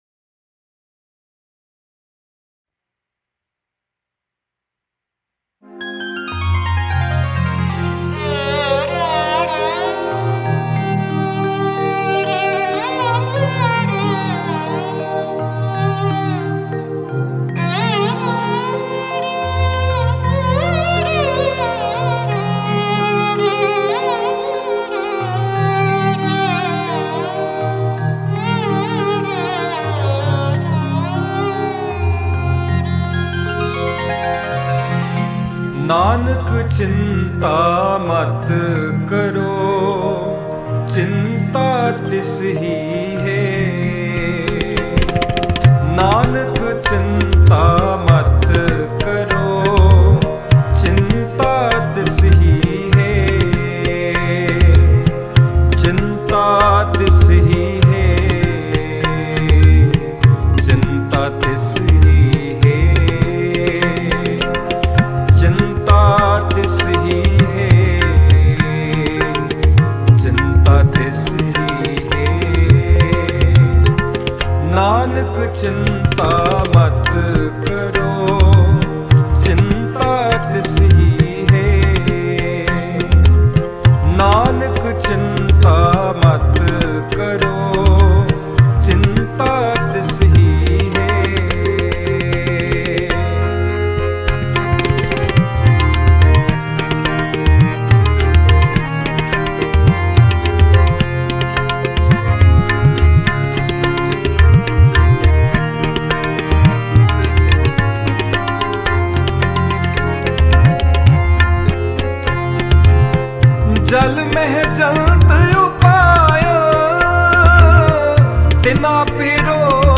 Guru nanak Dev Ji De Non Stop Shabad